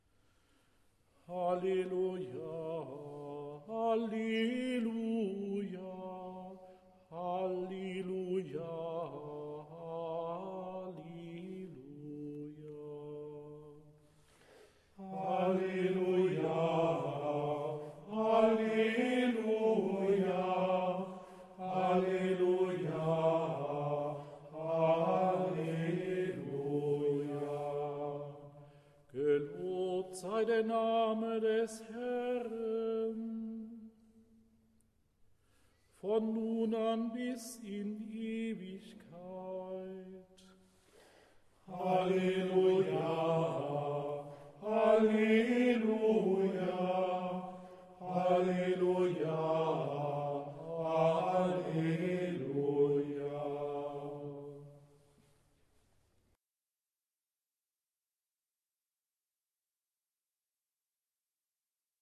B I T T E   B E A C H T E N S I E : Diese Gesänge sind nur zu Übungszwecken hier eingestellt.
Sie sind nicht von einer professionellen Schola gesungen und auch nicht unter Studiobedingungen aufgenommen.
Ordinarium_224_Halleluja_V_Ton.mp3